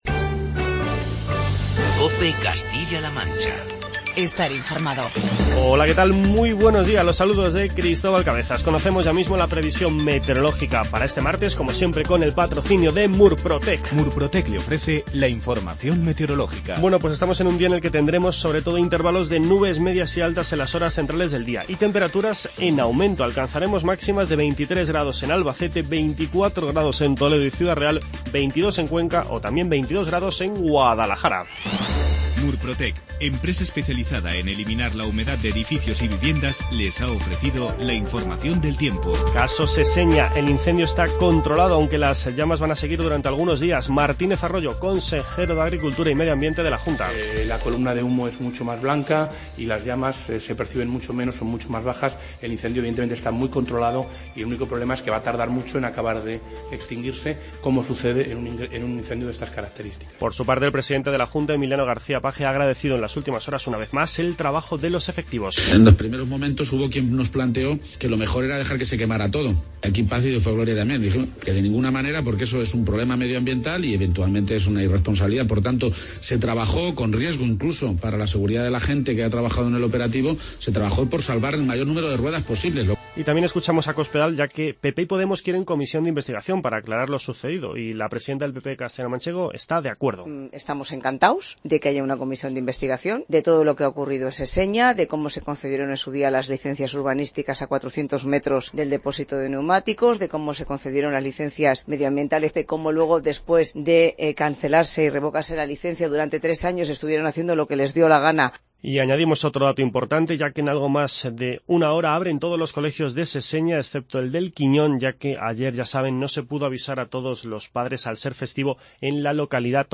Informativo regional
Escuchamos las declaraciones de Francisco Martínez Arroyo, Emiliano García-Page y María Dolores Cospedal con respecto al incendio de Seseña.